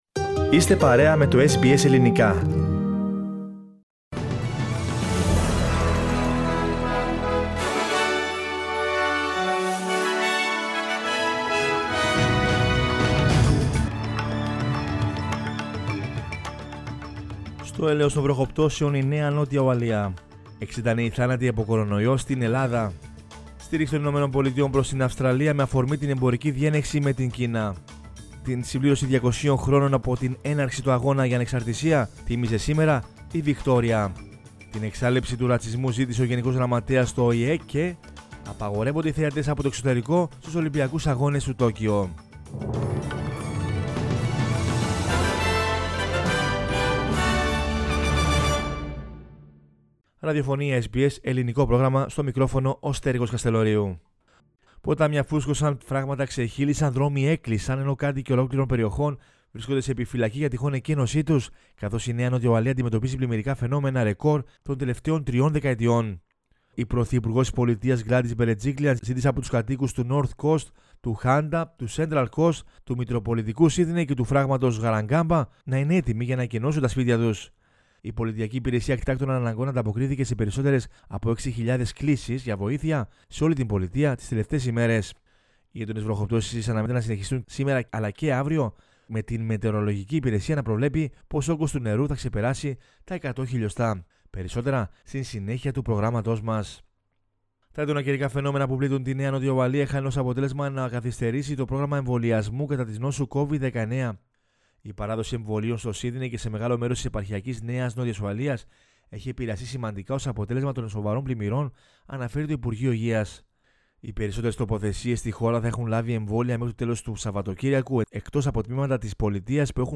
News in Greek from Australia, Greece, Cyprus and the world is the news bulletin of Sunday 21 March 2021.